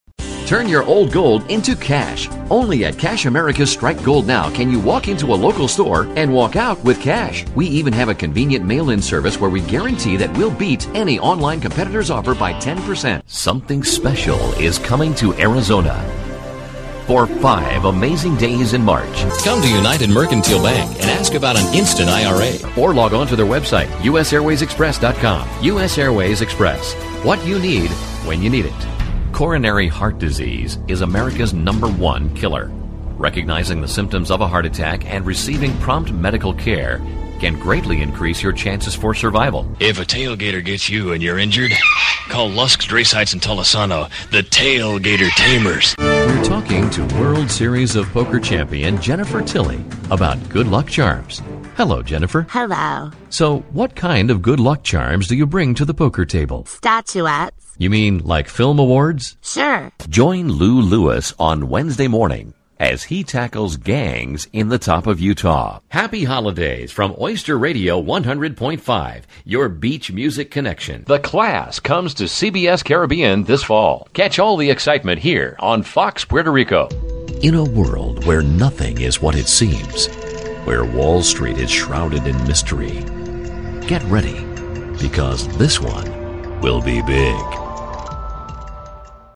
I can offer a warm, deep, personable voice over for narrations, training videos and presentations.
Sprechprobe: Werbung (Muttersprache):